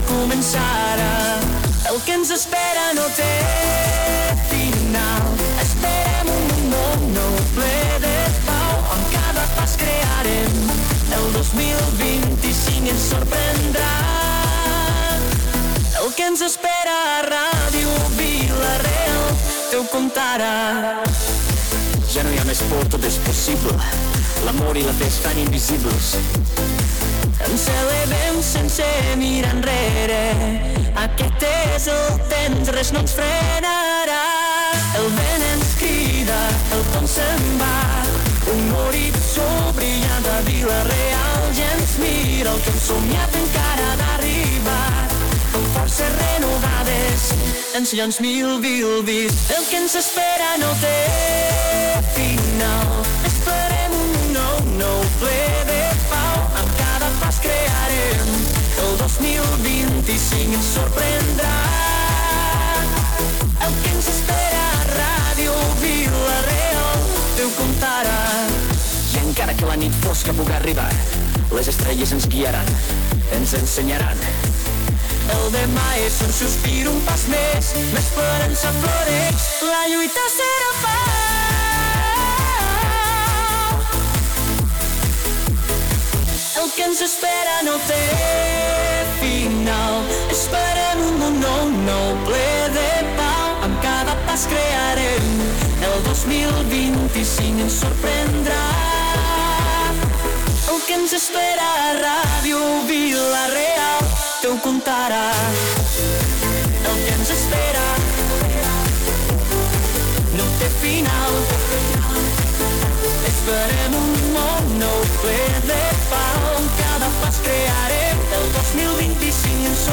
L´Alcalde de Vila-real, José Benlloch, fa balanç del 2024